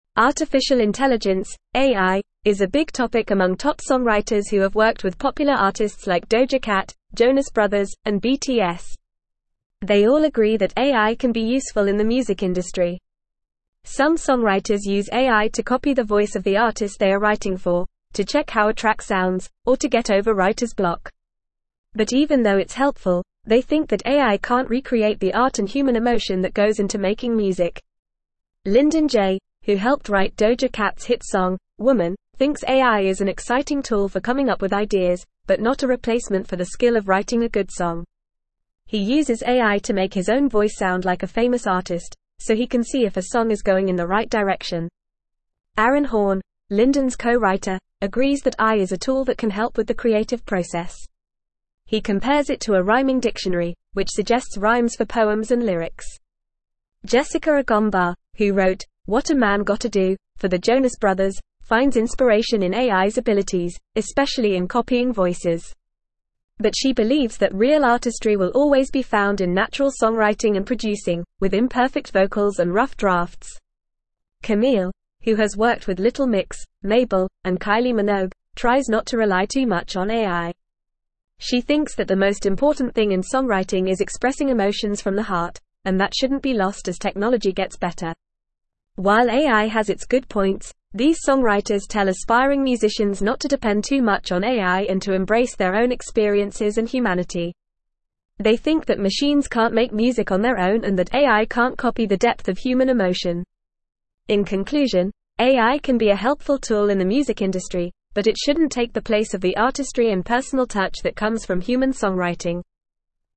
Fast
English-Newsroom-Upper-Intermediate-FAST-Reading-Top-Songwriters-Embrace-AI-as-a-Creative-Tool.mp3